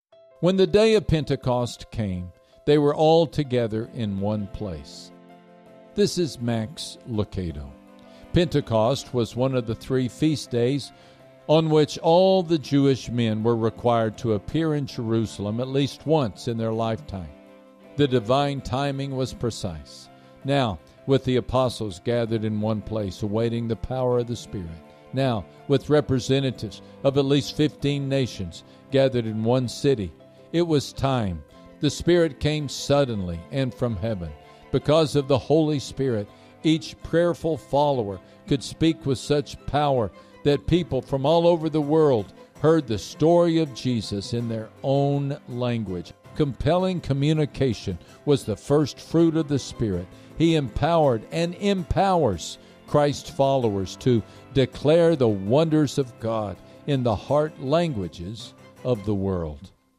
It doesn’t matter if it’s a good day, a hard day, or somewhere in-between—we need the hope God offers every day. Max shares words of hope and help through simple, one-minute daily devotionals to encourage you to take one step closer to Jesus.